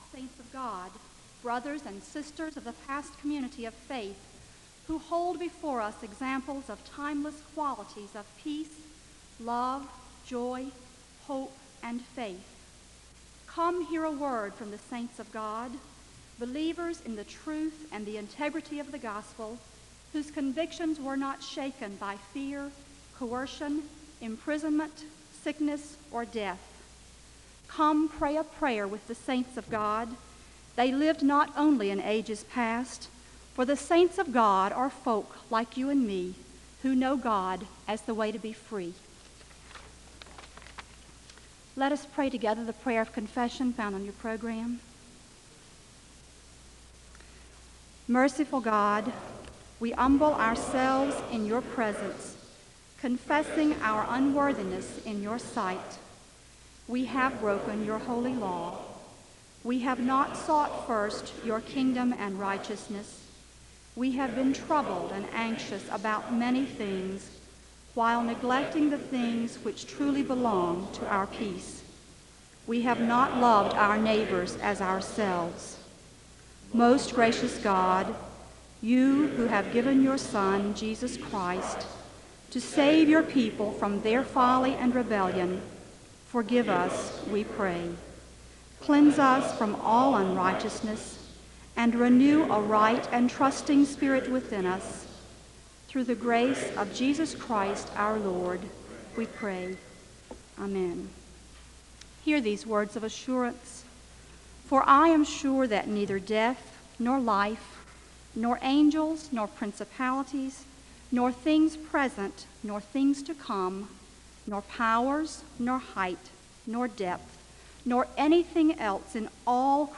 The service begins (abruptly) with a recitation regarding the saints of God (0:00-0:40). A prayer of confession follows (0:41-1:41).
The choir sings a song of worship (2:12-5:52).
The choir sings another song of worship (26:23-28:28).